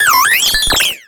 Cri de Grelaçon dans Pokémon X et Y.